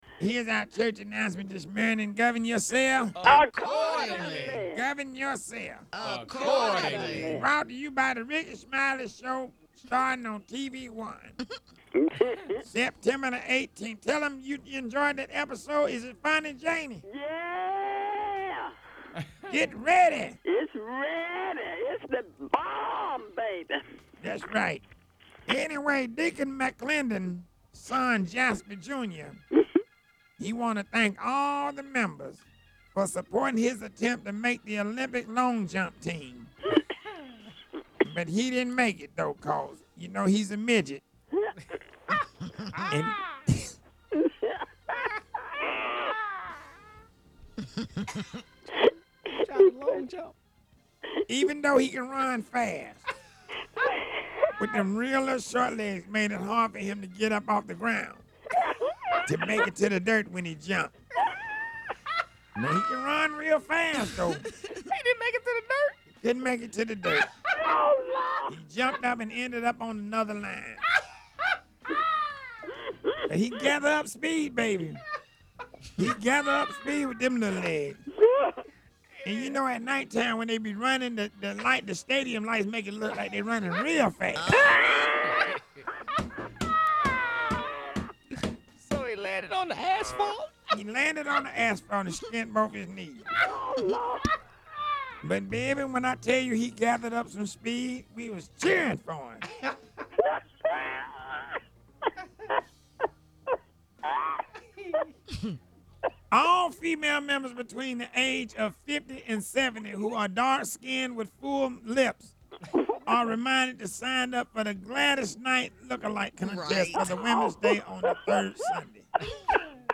CLICK TO HEAR MONDAY’S CHURCH ANNOUCEMENTS!